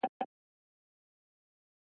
user_online.mp3